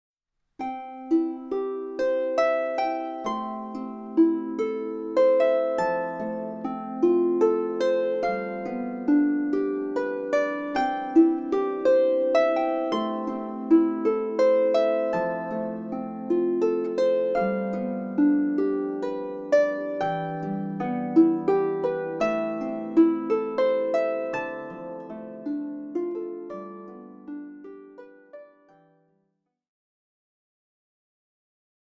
Hörbuch
5-Minuten-Märchen zum Lauschen Teil 1 15 Märchen & Harfenmusik